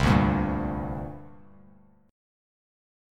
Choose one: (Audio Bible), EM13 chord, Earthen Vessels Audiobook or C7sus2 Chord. C7sus2 Chord